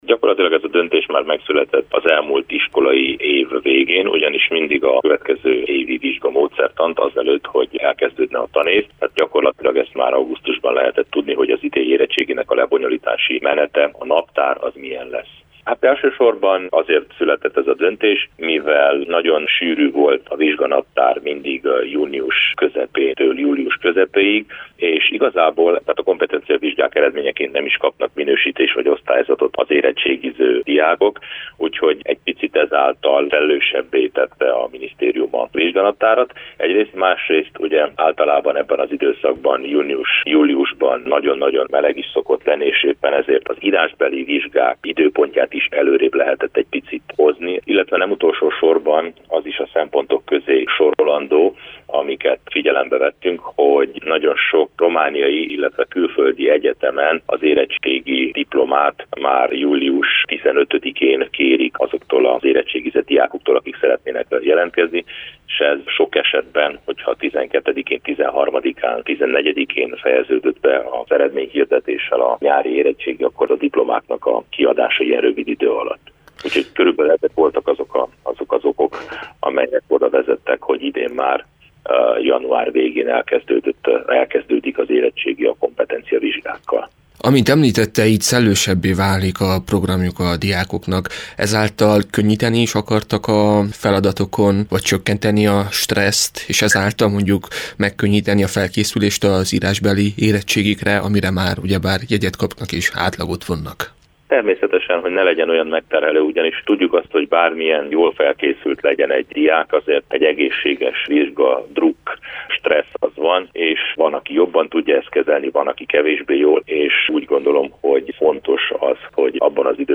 Miért döntött a tanügyminisztérium a kompetenciavizsgák időpontjának az előrehozatala mellett? – kérdeztük Kallós Zoltán tanügyi államtitkárt.